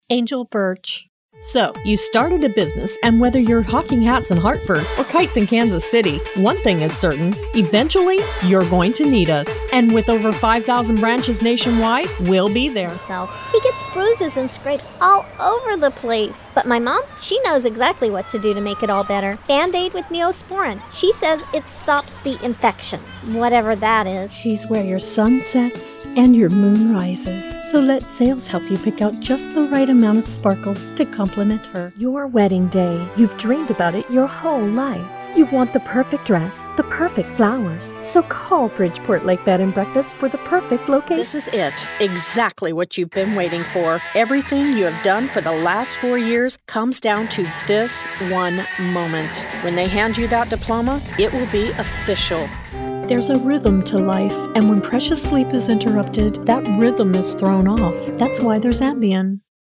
Voice Intonations: Bright, Energetic, Professional, Calming, Adult, Millennial, Child, Senior
englisch (us)
Sprechprobe: Werbung (Muttersprache):